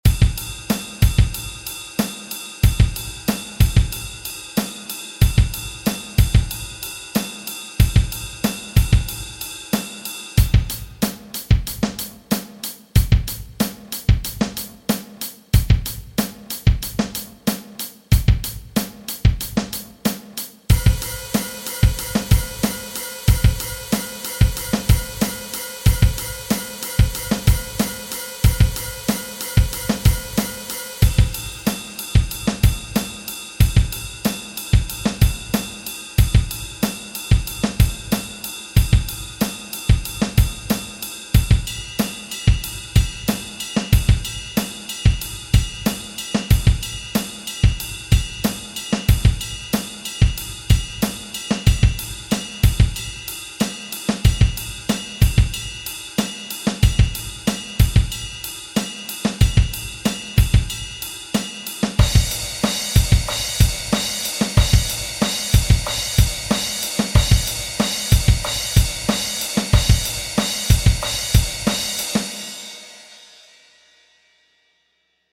Die reine Notation und das Hörbeispiel sind ohne Shuffle.
groove_improvisation.mp3